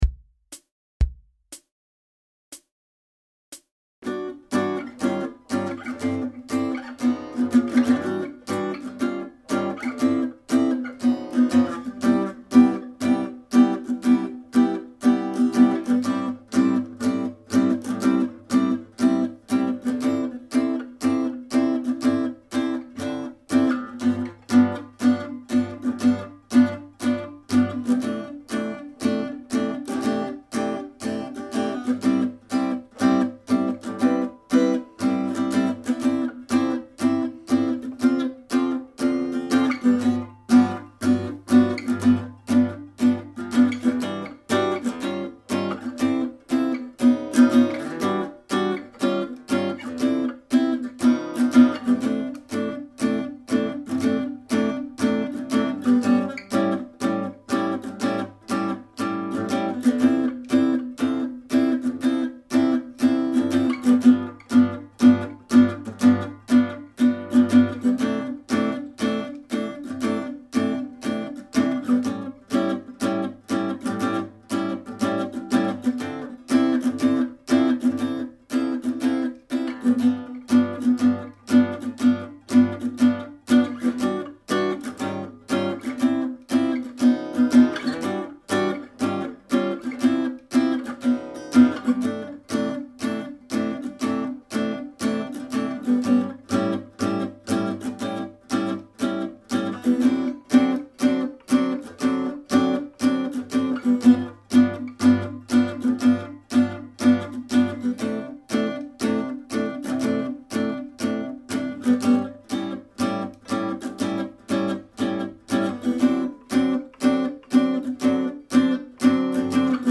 Accompagnement guitare seule (tempo 120) :